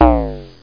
lowbing.mp3